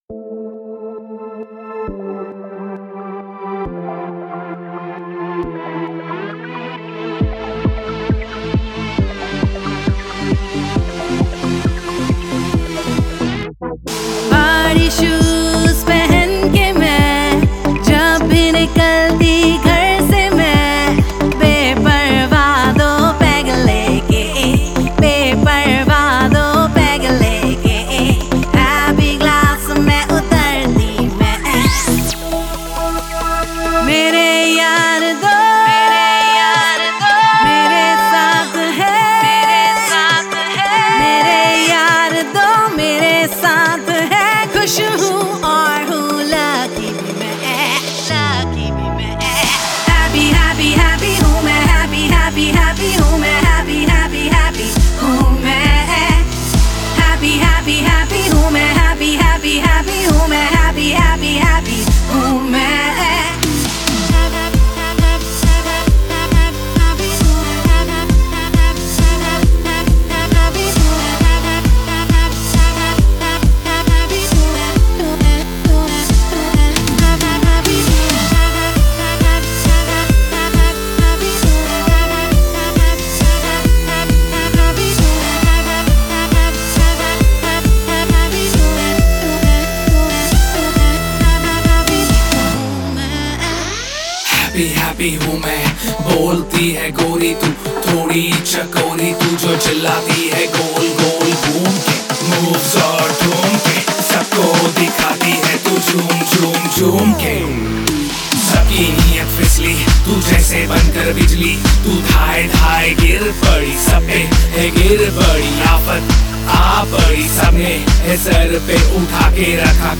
INDIPOP MP3 Songs
IndiPop Music Album